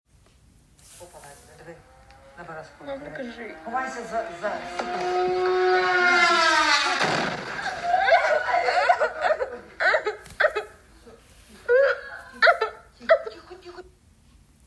Drone-inslag in Vylkove
drone-explosion.mp3